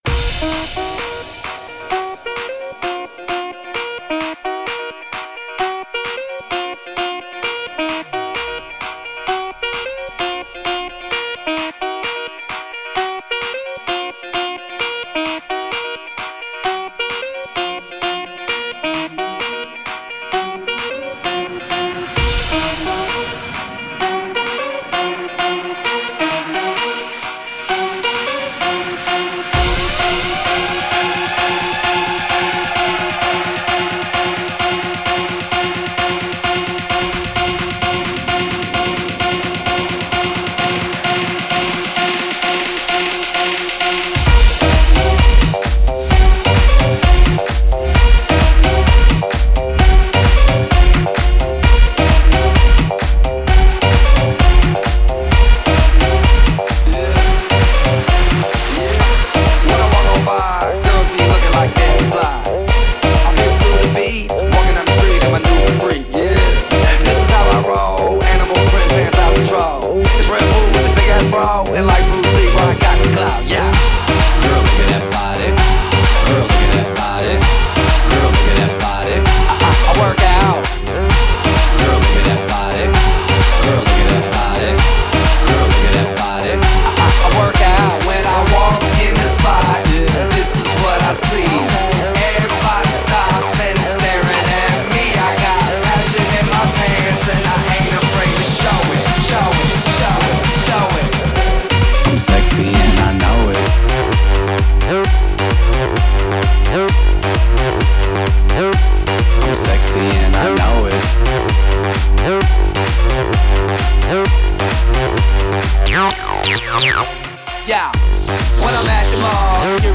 Sesión veraniega de 2012